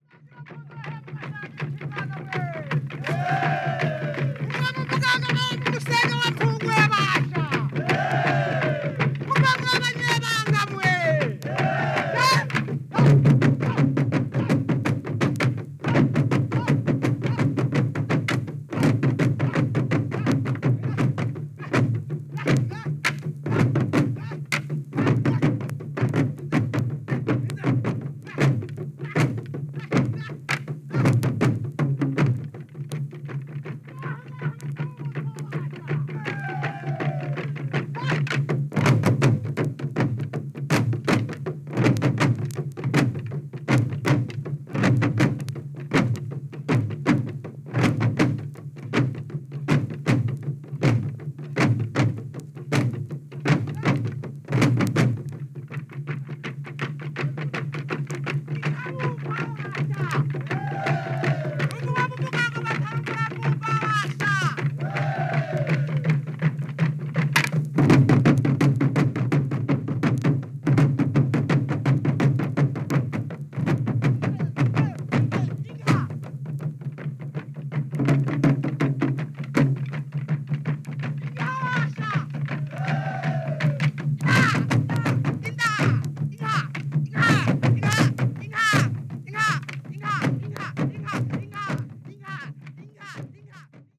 "Tambours "Ingoma""
Title : Musiques Traditionnelles